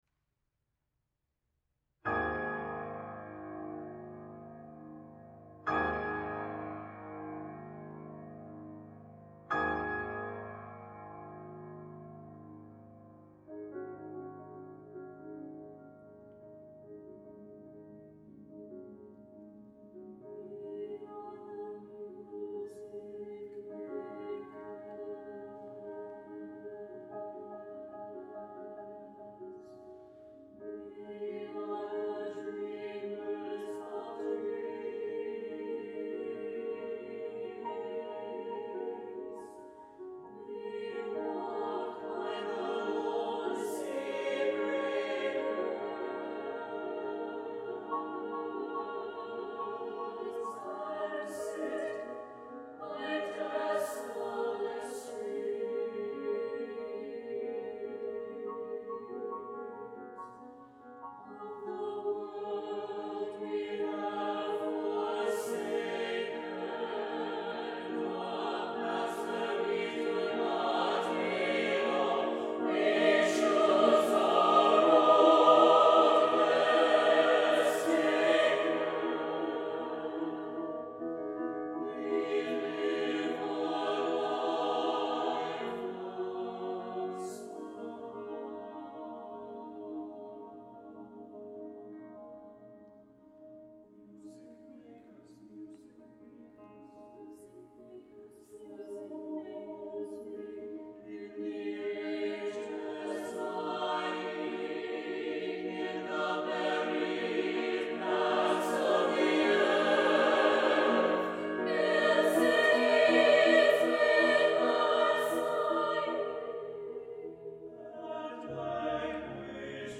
for SATB Chorus and Piano (2003)